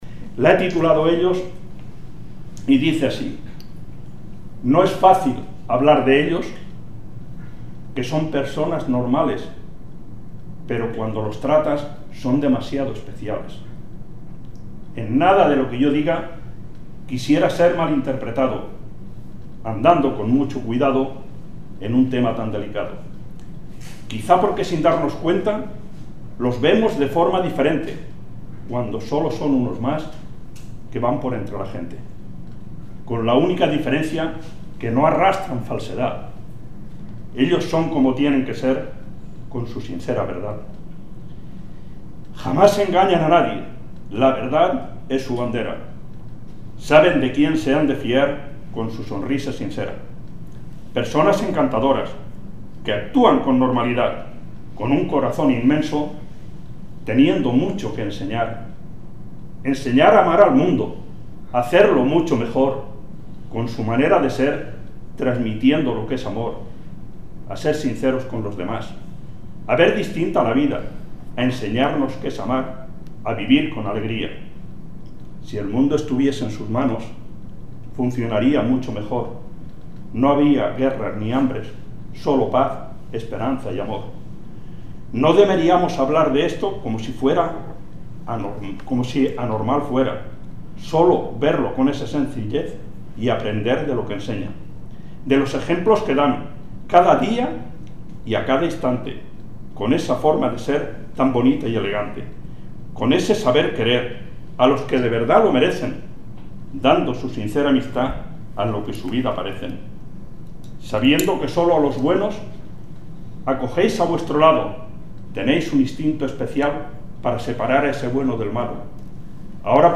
Con motivo del día internacional de la Diversidad Funcional que se conmemora hoy martes 3 de diciembre, la Asociación Iguals i Sense Través, ha llevado a cabo la presentación del libro El Renacer de los Girasoles.